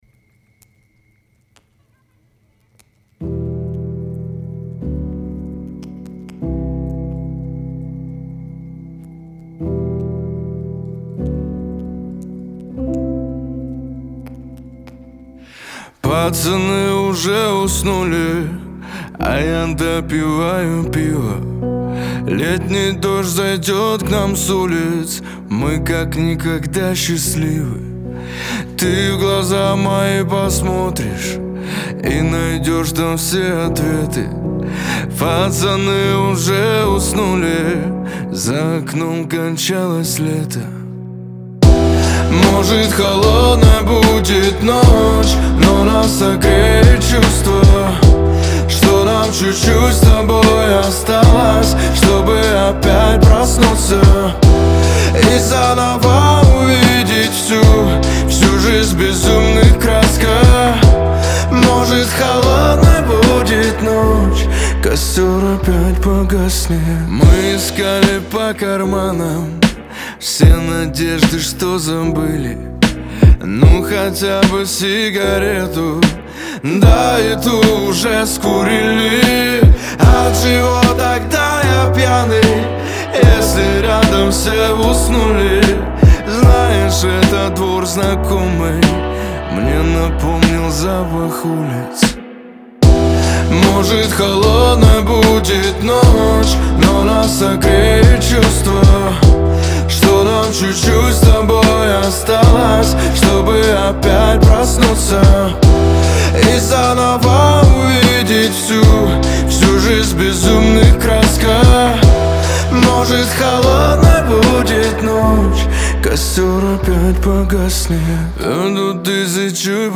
мелодичные куплеты с ритмичным хип-хопом